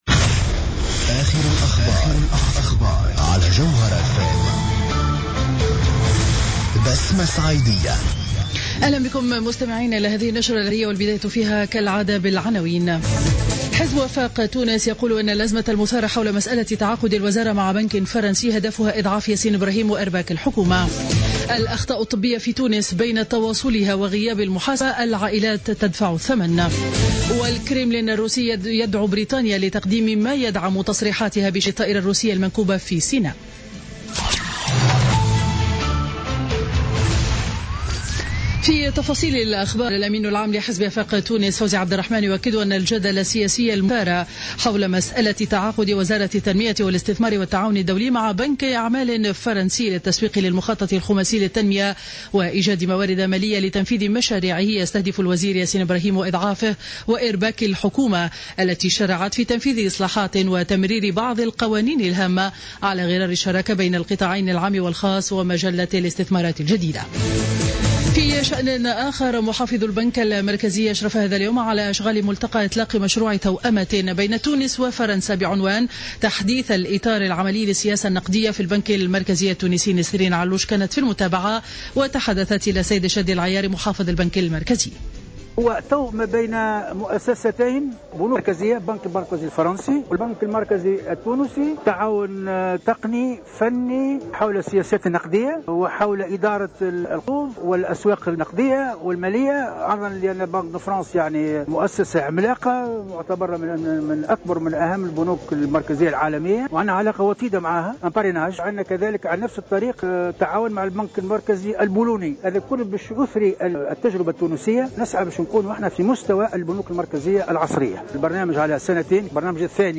نشرة أخبار منتصف النهار ليوم الخميس 5 نوفمبر 2015